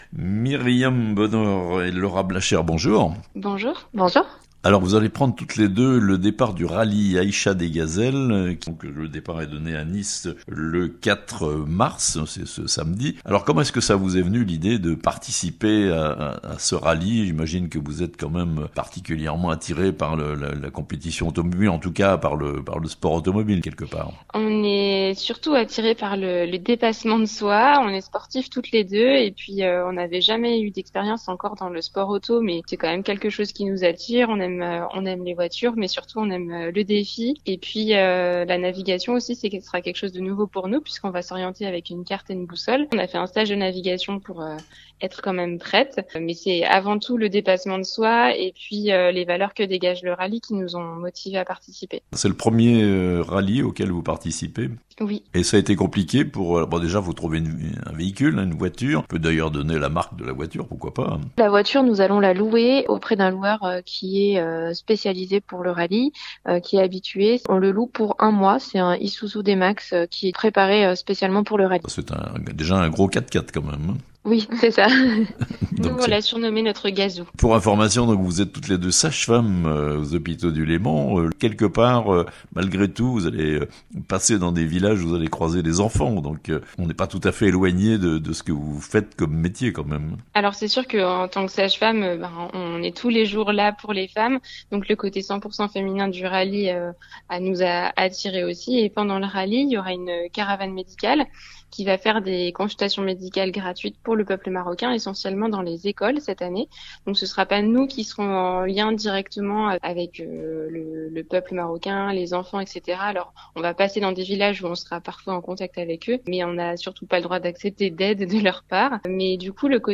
Les "Gazelles du Chablais" à l'assaut du désert marocain (interviews)